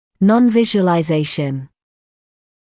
[ˌnɔnˌvɪʒuəlaɪ’zeɪʃn][ˌнонˌвижуэлай’зэйшн]невизуализация; непросматривание